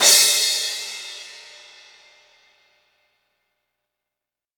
• Big Crash Cymbal Audio Clip C Key 01.wav
Royality free crash cymbal one shot tuned to the C note.
big-crash-cymbal-audio-clip-c-key-01-qSt.wav